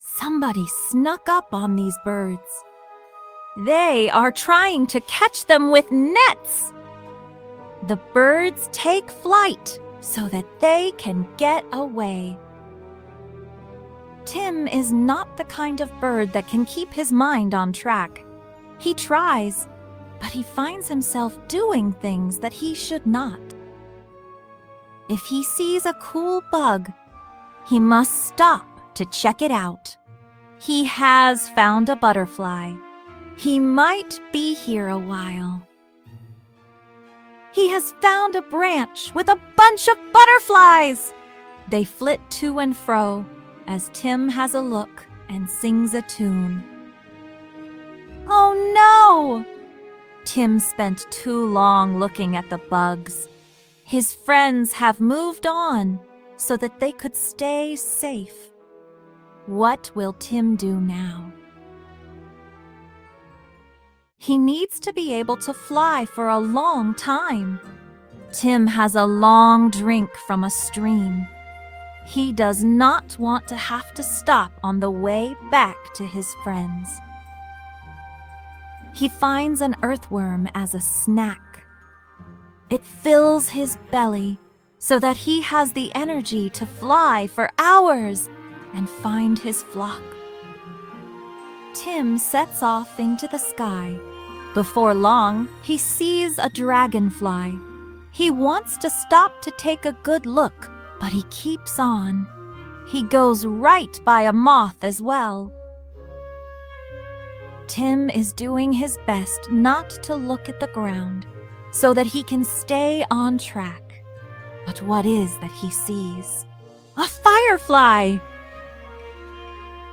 Free Adventure Decodable Reader with Compound Words | Got to Go!